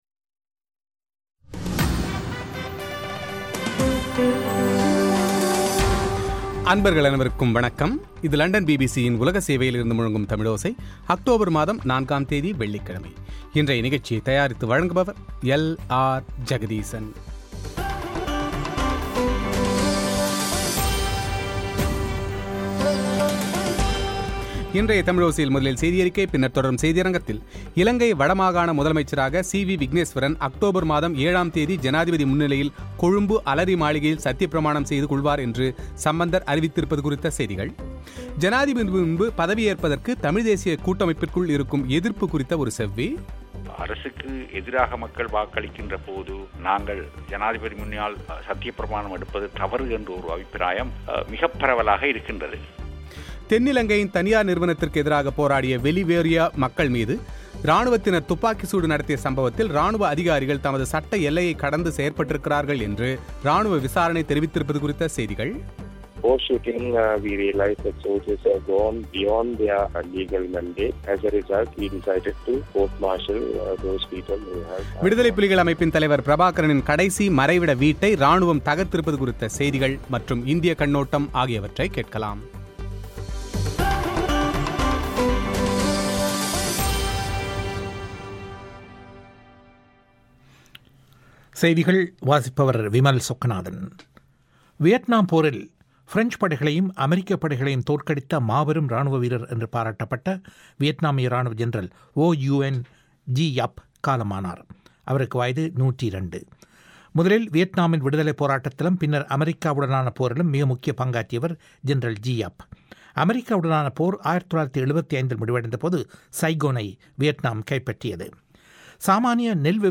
வட மாகாண முதல்வர், ஜனாதிபதி முன் பதவிப் பிரமாணம் செய்து கொள்வது குறித்து, தமிழ் தேசியக் கூட்டமைப்புக்குள் கருத்து வேறுபாடு நிலவியதாக வரும் செய்திகளில் உண்மை இருக்கிறதா, என்று கூட்டமைப்பின் சார்பாக வடமாகாண சபைக்குத் தேர்ந்தெடுக்கப்பட்டவரும், ப்ளாட் அமைப்பின் தலைவருமான தர்மலிங்கம் சித்தார்த்தன் செவ்வி;